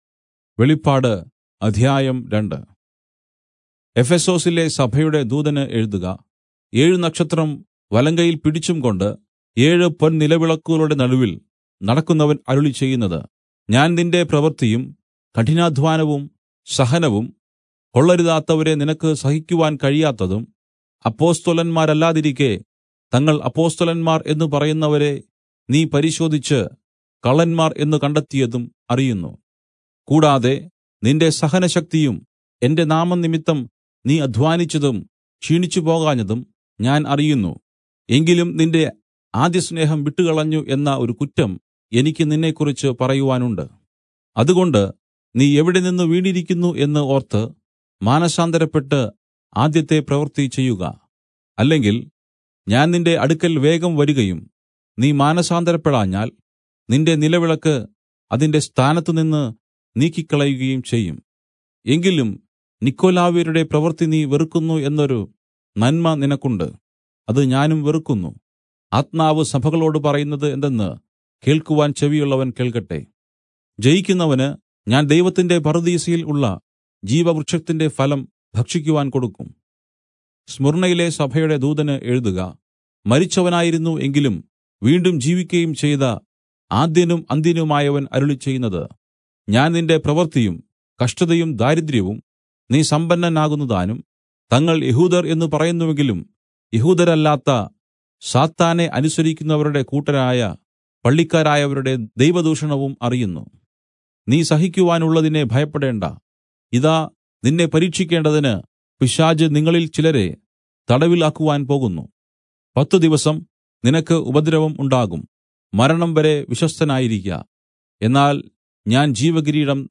Malayalam Audio Bible - Revelation 2 in Irvml bible version